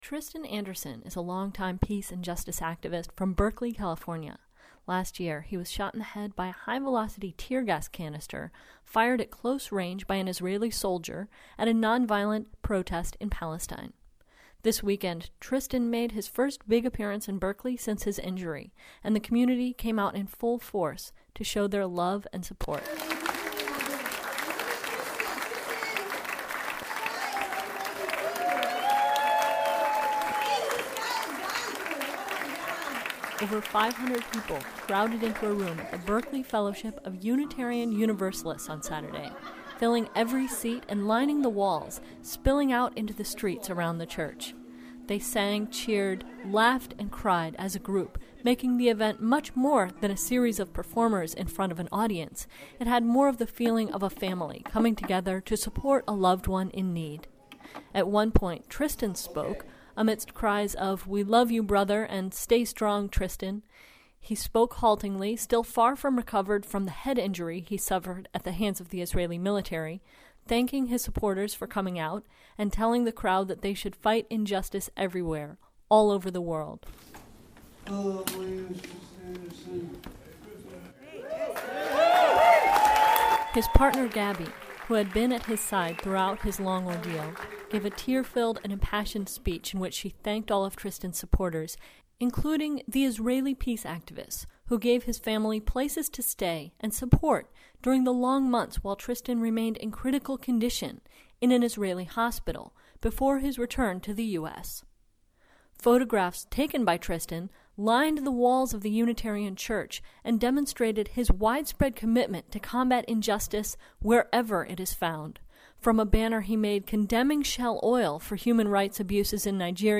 Audio report: 4m15s
Over 500 people crowded into a room at the Berkeley Fellowship of Unitarian Universalists on Saturday, filling every seat and lining the walls, spilling out into the streets around the church.
They sang, cheered, laughed and cried as a group, making the event much more than a series of performers in front of an audience.